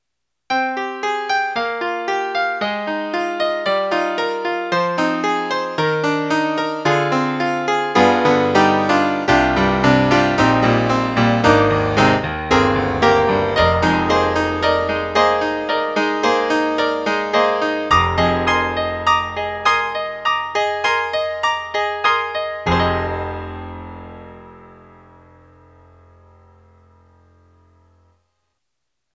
特集：徹底比較！DTM音源ピアノ音色聴き比べ - S-studio2
000_StereoPiano
E-MU_Proteus1_PopRock_000_StereoPiano.mp3